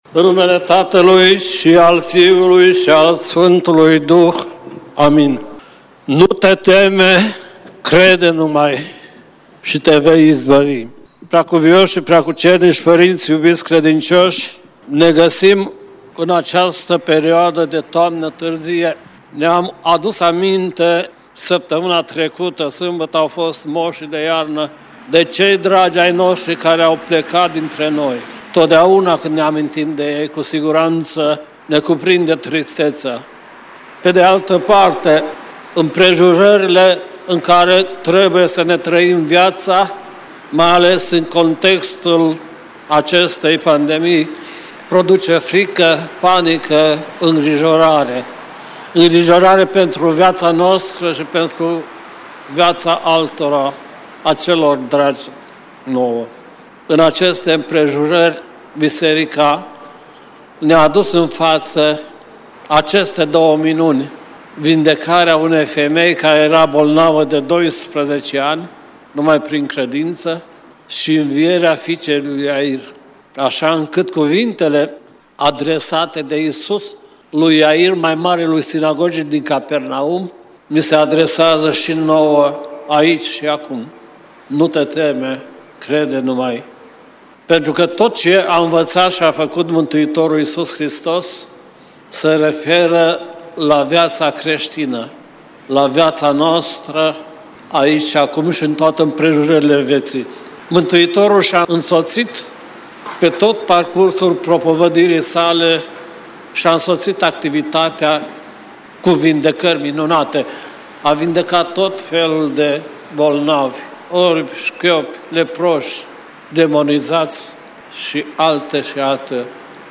Cuvinte de învățătură Predică la Duminica a 24-a după Rusalii